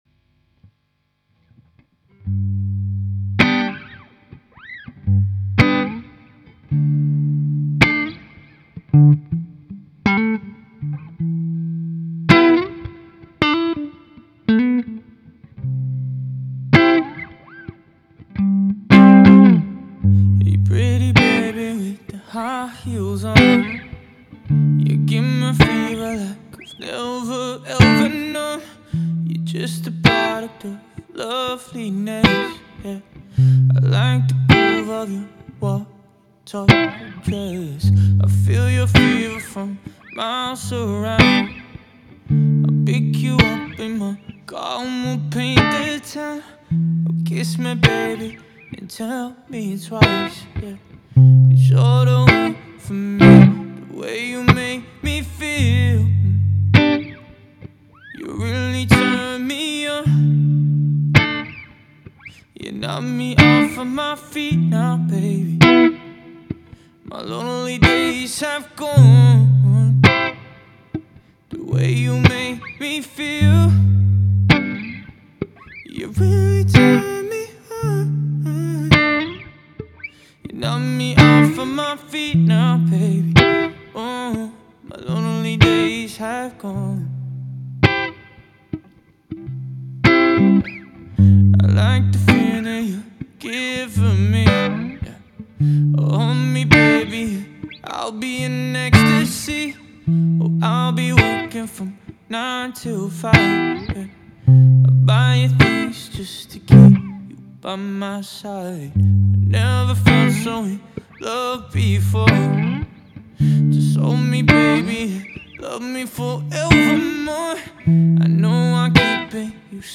Vocals | Guitar | Looping | DJ